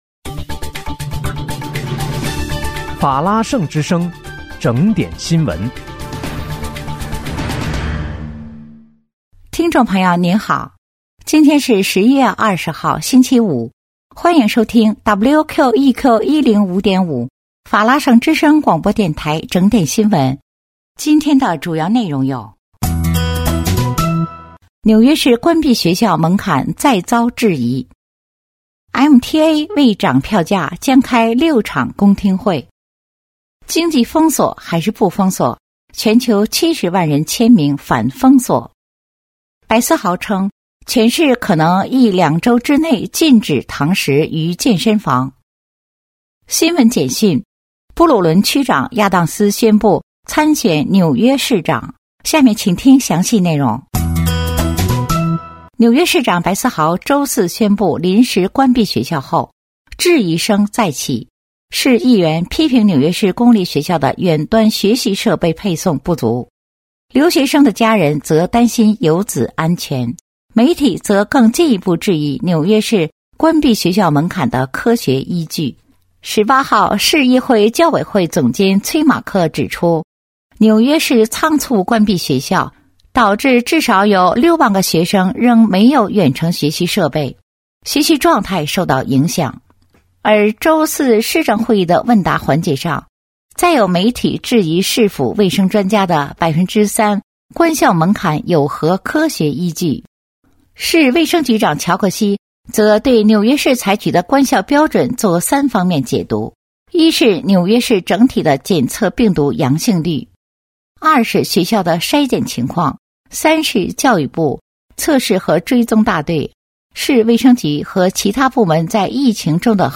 11月20日（星期五）纽约整点新闻